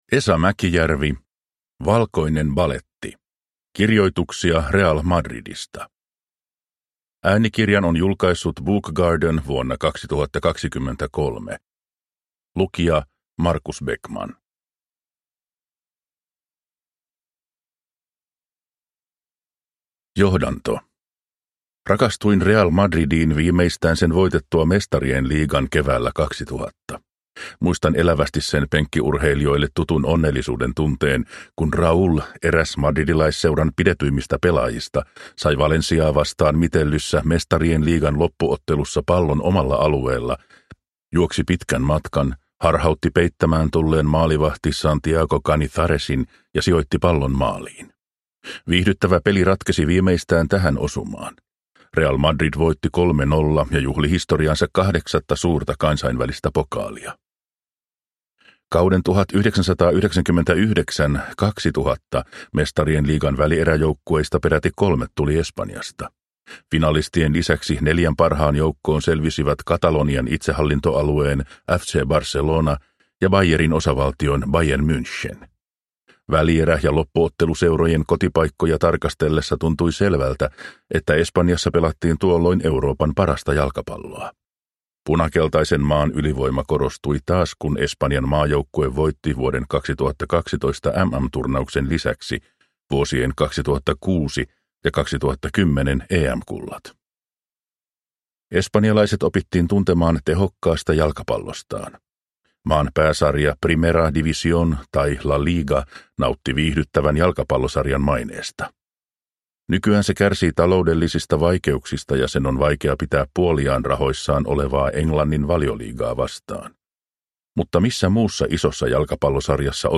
Valkoinen baletti – Ljudbok – Laddas ner